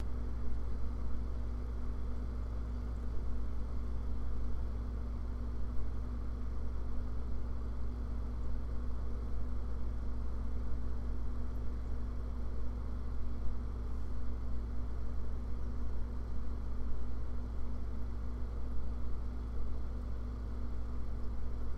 冰箱电机底噪
描述：冰箱电机底噪，家庭厨房环境录制
标签： 厨房 电力 冰箱 电机 家电 环境音
声道立体声